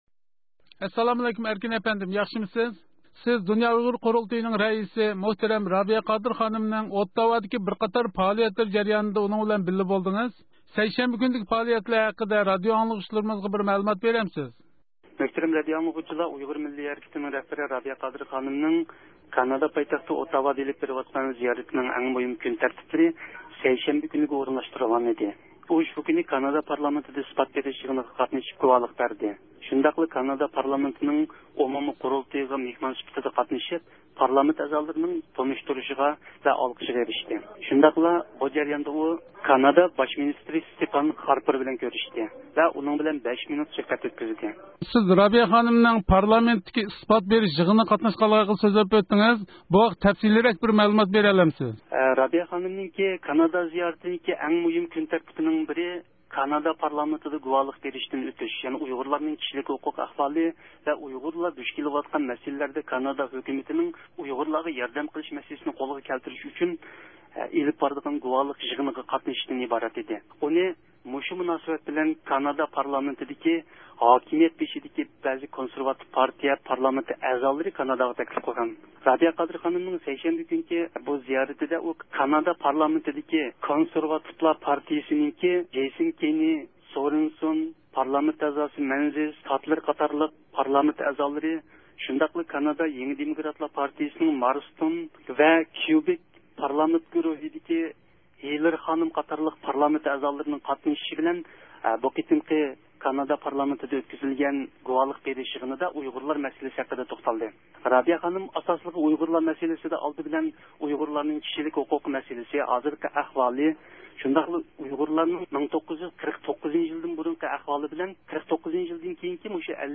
رابىيە قادىر خانىم كانادا پارلامېنتىدا ئۇيغۇرلارنىڭ كىشىلىك ھوقۇقى ھەققىدە گۇۋاھلىقتىن ئۆتتى